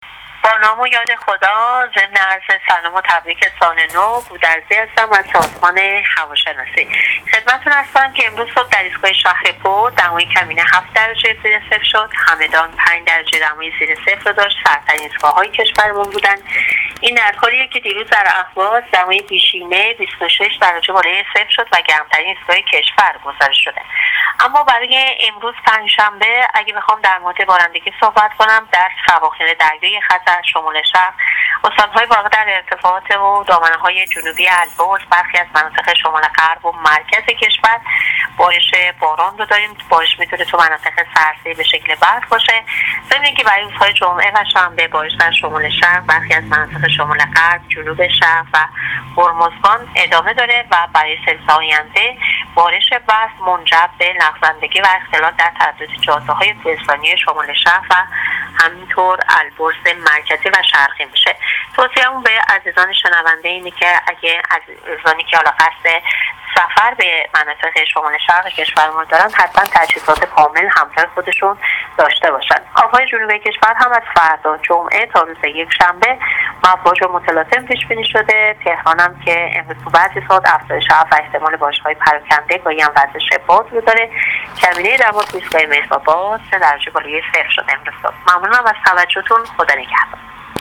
گزارش آخرین وضعیت جوی کشور را از رادیو اینترنتی پایگاه خبری وزارت راه و…